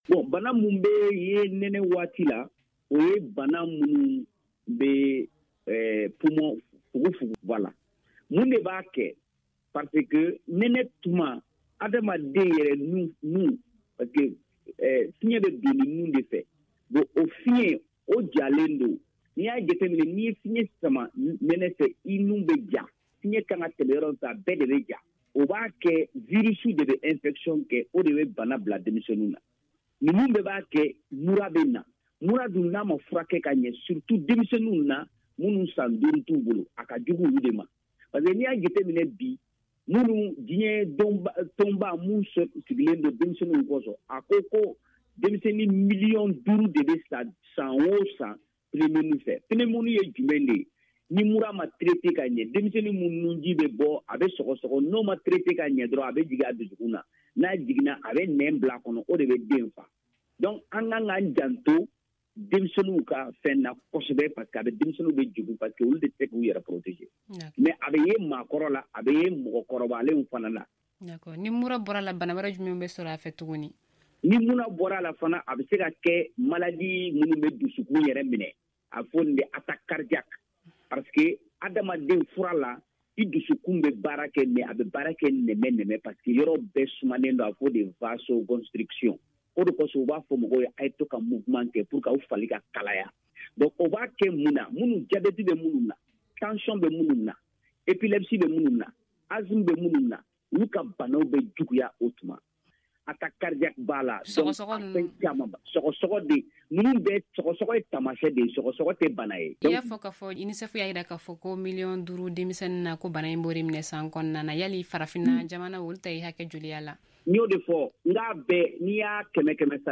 Magazine en bambara: Télécharger